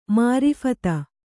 ♪ māriphata